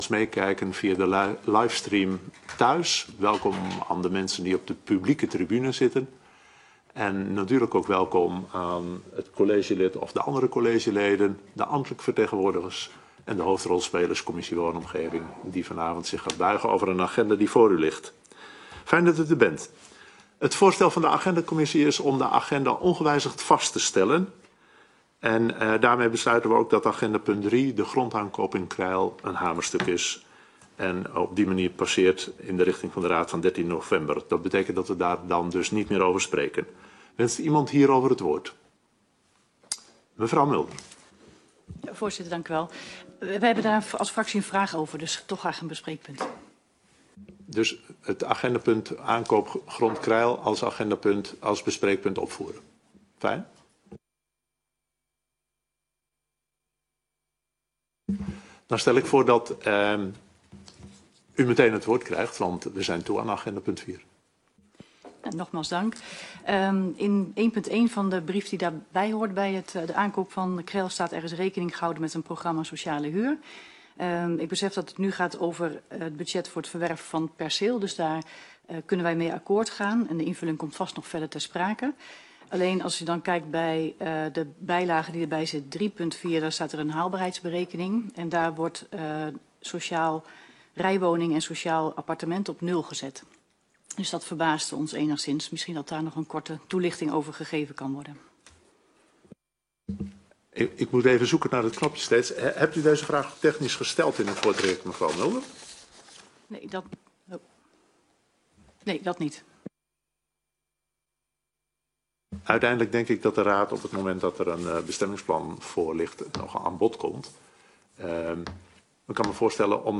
Vergadering van de commissie Woonomgeving op dinsdag 31 oktober 2023, om 19.30 uur, fysiek in de kamer 83 van het gemeentehuis.